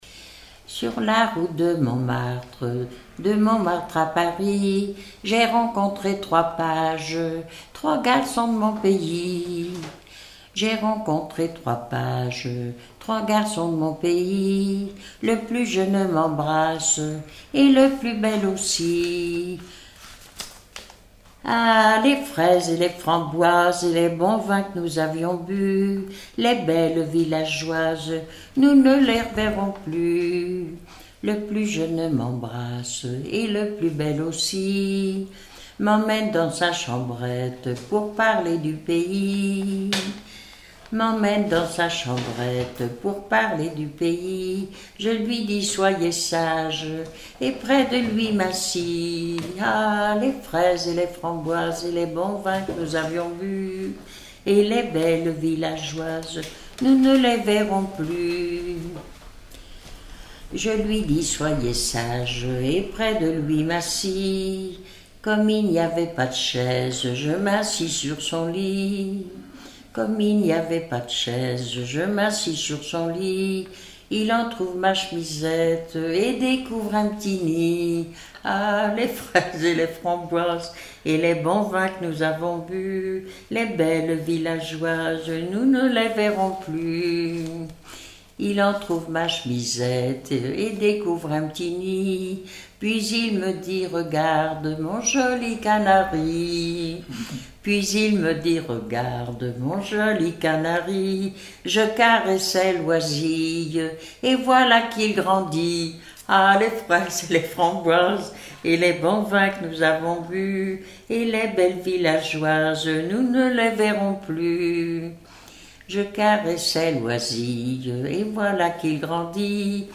Mémoires et Patrimoines vivants - RaddO est une base de données d'archives iconographiques et sonores.
Chansons traditionnelles et populaires
Pièce musicale inédite